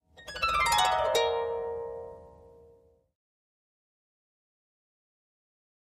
Harp, Medium Strings, 7th Chord, Short Descending Gliss, Type 1